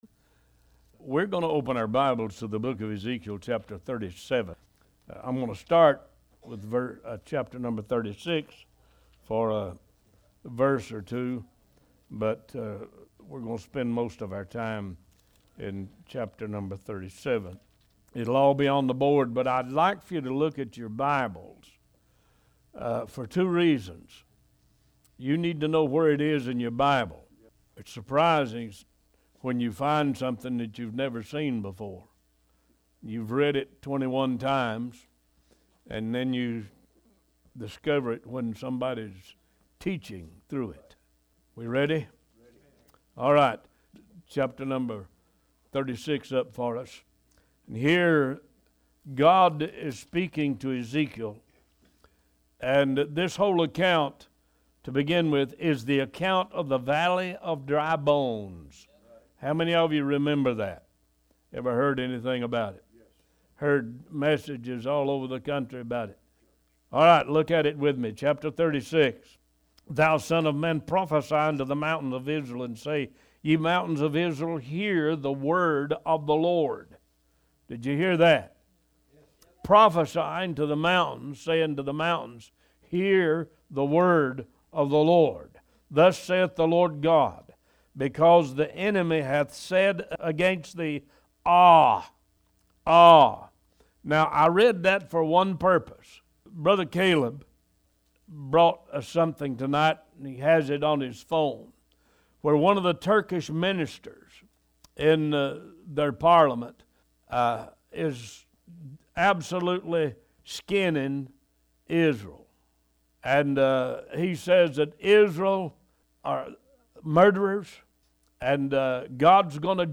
One Voice Talk Show